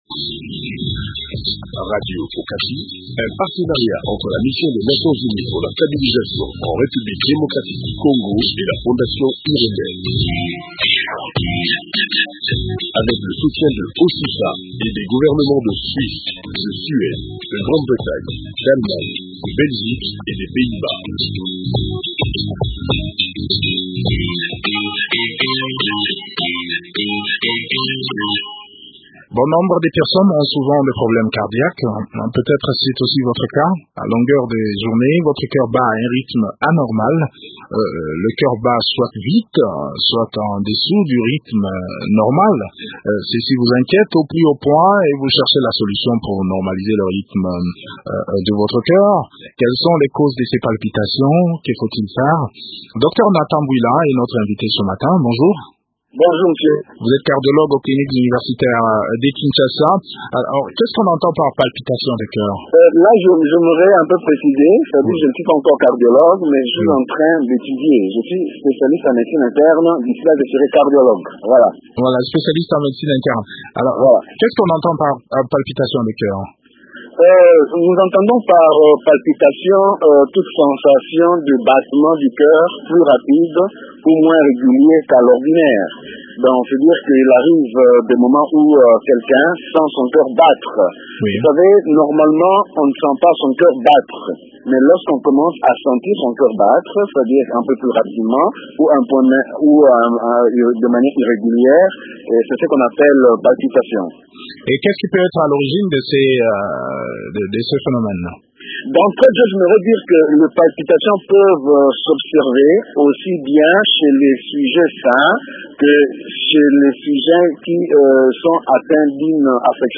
Découvrez quelques précautions de lutte contre les palpitations de coeur dans cet entretien